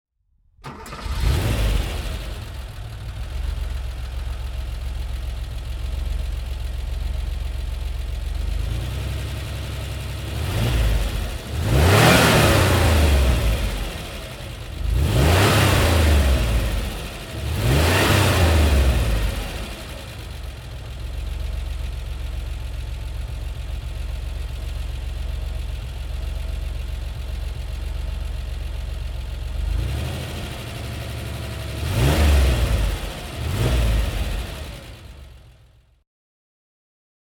Porsche 911 SC Cabriolet (1983) - Starten und Leerlauf